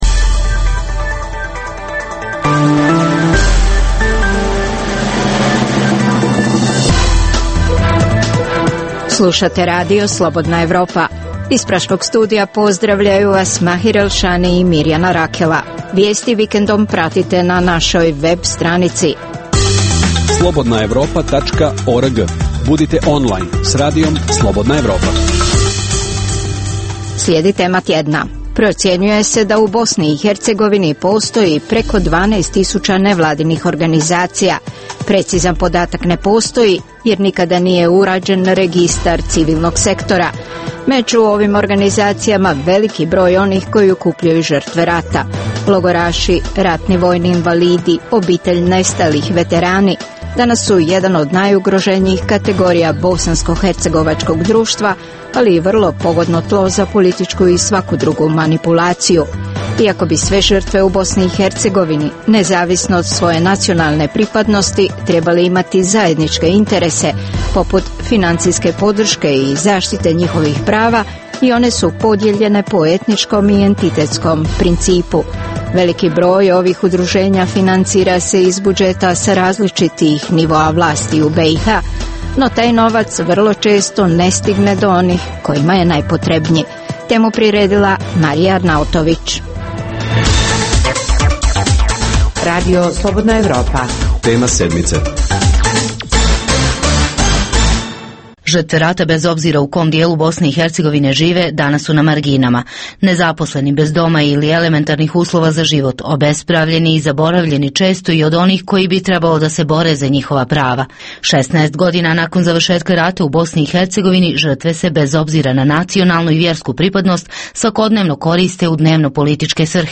U emisiji možete čuti: - Srbi sa sjevera Kosova postavljali, pa uklanjali barikade. Na jednoj od barikada naši reporteri su bilježili kako "graditelji" obrazlažu svoje "akcije", što kažu o Beogradu, što o Prištini.